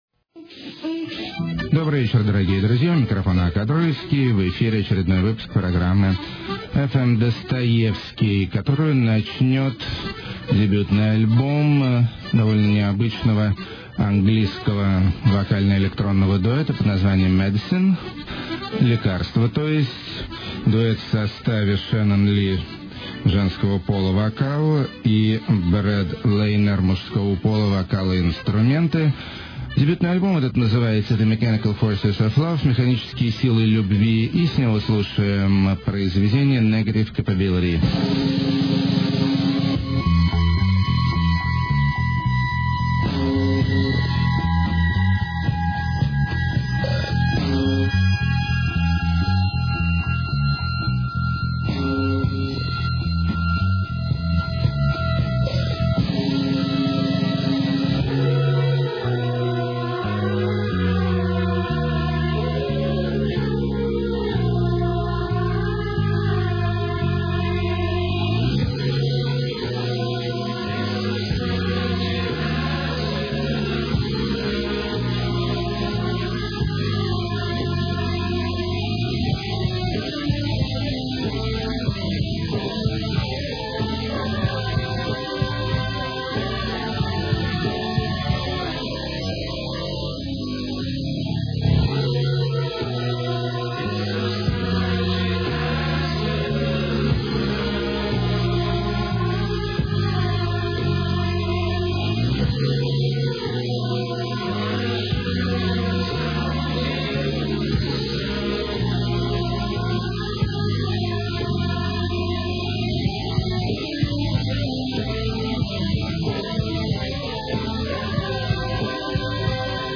the weird edge of electro-pop
post-punk, fresh sound
rocky accordeon
schmaltzy country surf
adventurous electro
lush pop flamenco flavoured
sensous downtempo
post-krautrock
intellectual cosmopolitan trip-pop
leftfield indie eclecticism
mandarin sexshop disco